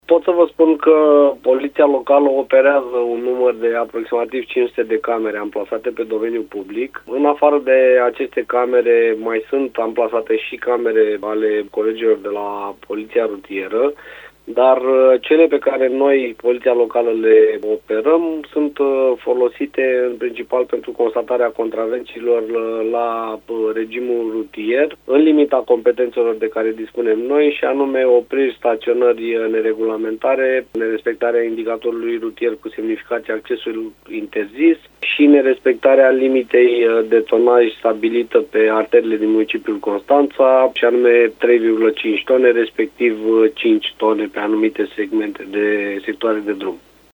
Directorul acesteia, Ionuț Dumitru: